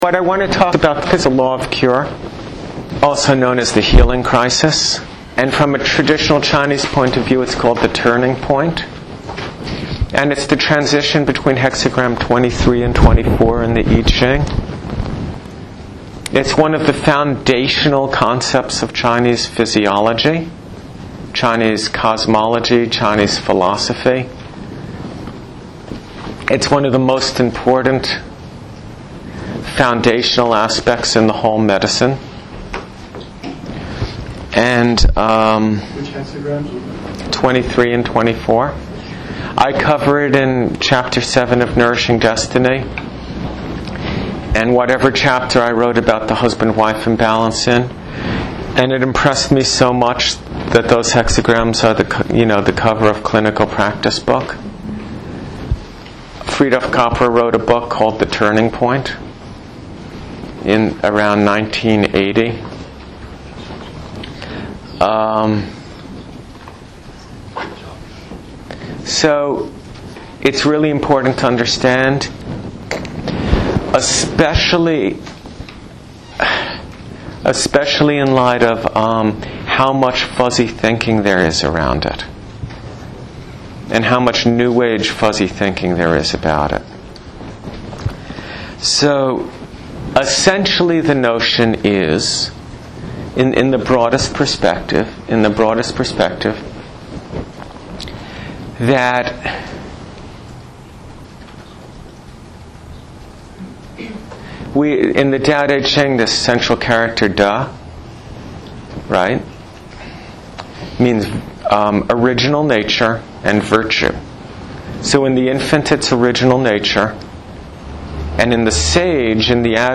This is a lecture given to me own students on 5.9.09 as part of my two year Clinical Integration Course, You can see the details of that course and my teaching schedule, By Clicking HERE